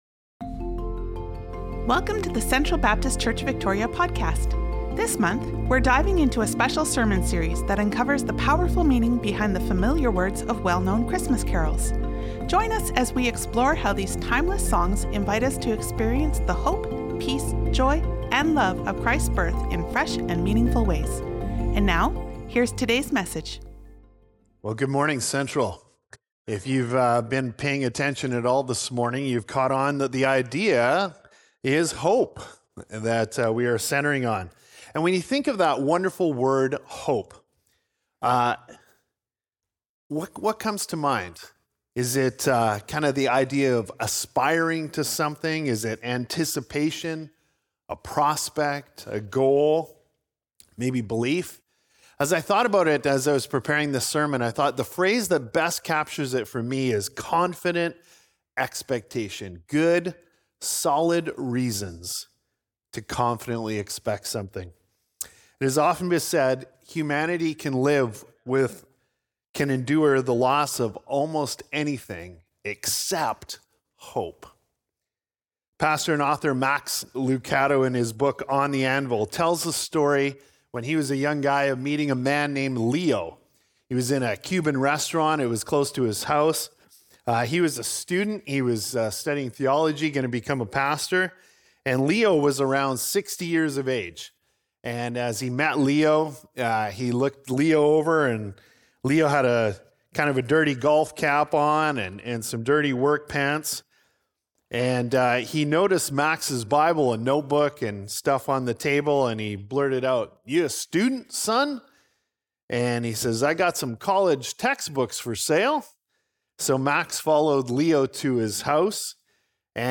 6-7 Sermon Notes Dec 1'24.Worship Folder.pdf Dec 1'24.Sermon Notes.